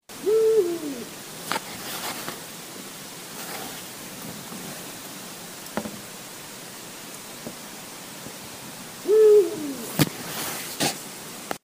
Owl Hooting - sound adjusted
My phone recorded it quite quietly before, so here it is again!